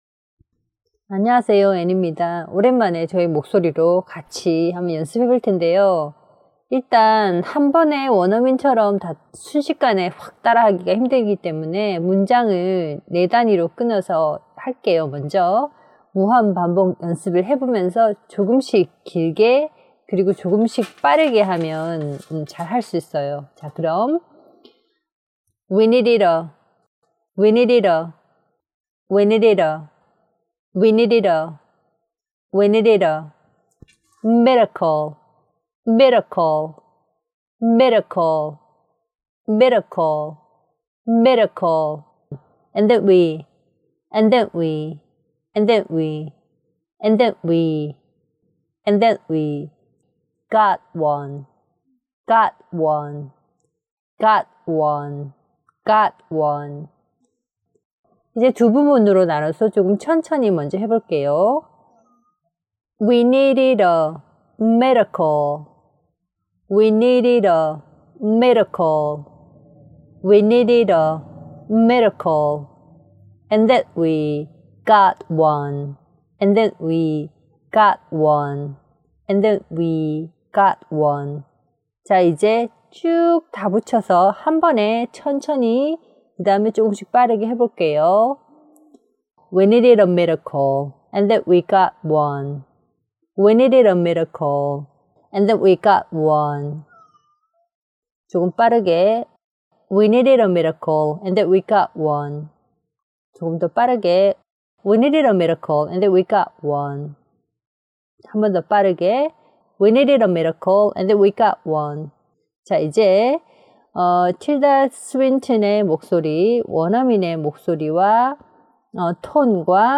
위니리러 미러컬 ..앤댄 위 가앗 원
이 문장에서는 need(동사), miracle(명사), got(동사)를 길고, 강하게 강조해주면 됩니다.
북미식 발음은 d, t가 모음 사이에 오면 약하게 "르“로 발음하니까요.